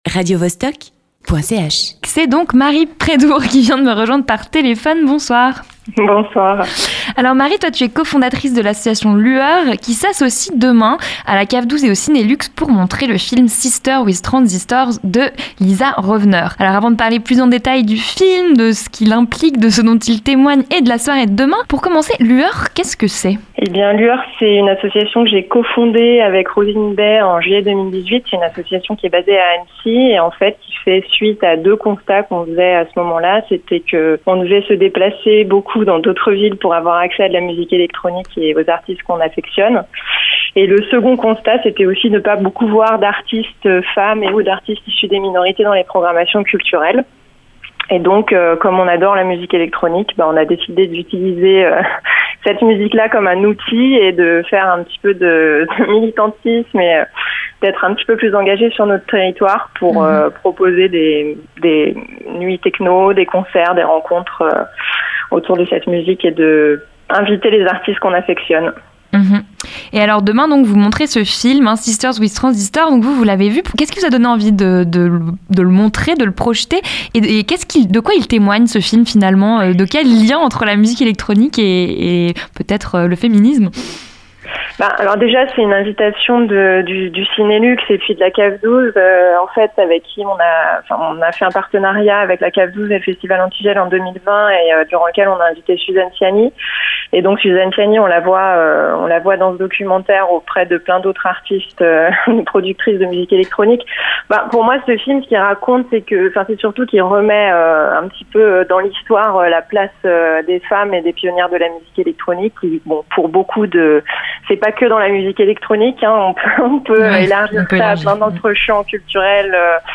Invitée :
Animation :